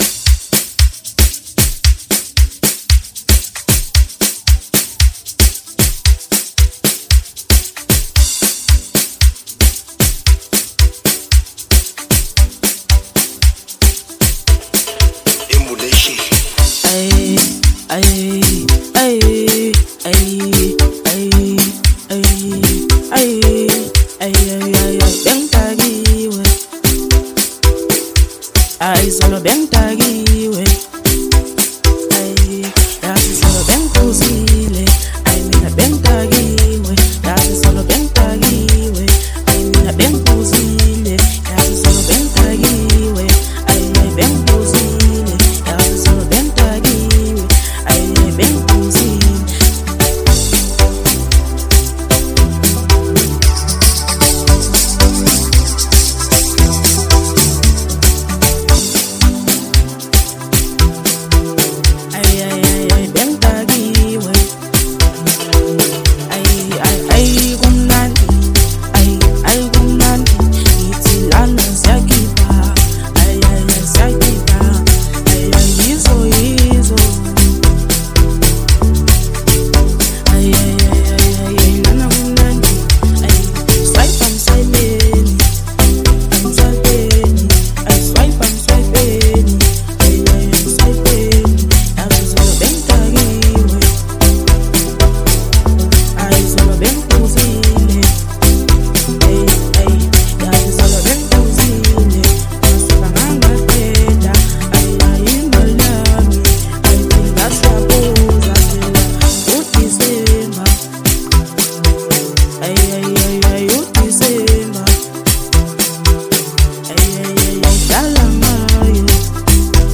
06:52 Genre : Amapiano Size